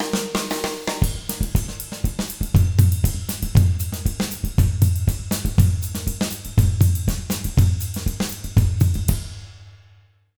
240SAMBA05-L.wav